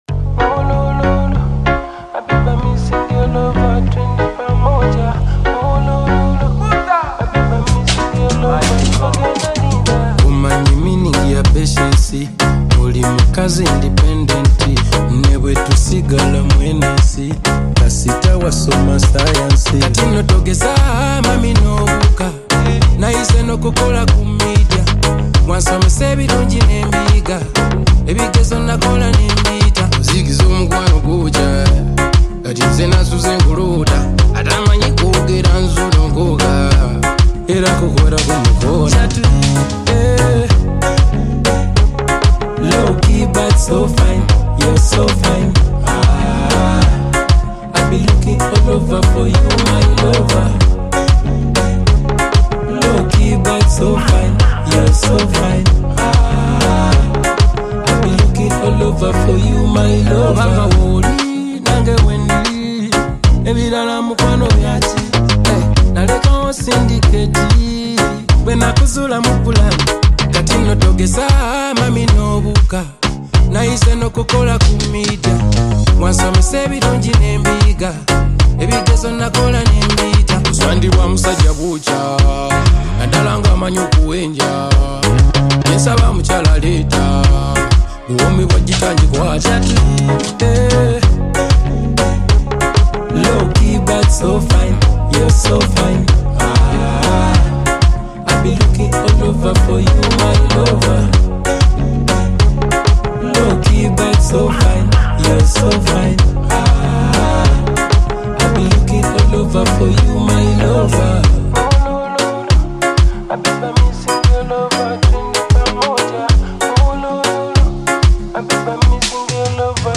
Genre: Tanzanian Songs